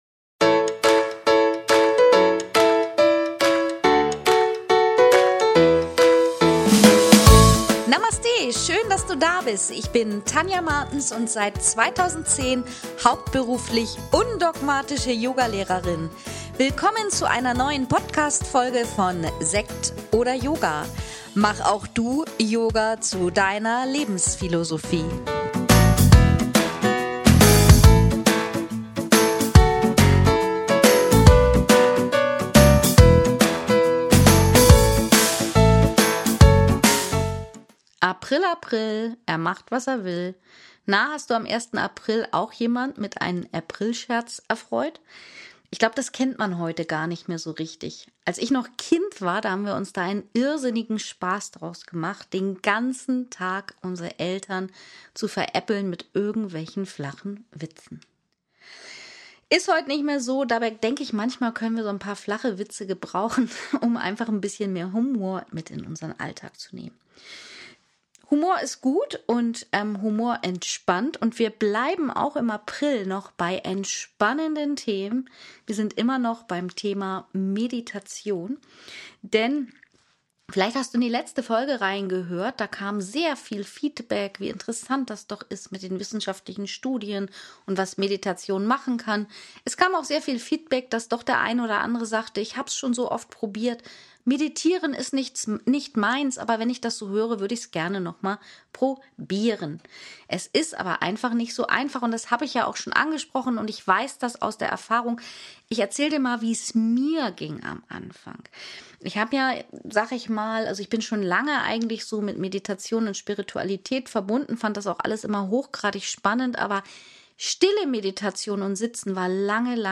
Erfahre heute etwas über grundlegende Techniken, wie ich meditiere, wie du eine Meditationspraxis in deinen Alltag bringst. Und zum Abschluss meditieren wir gemeinsam.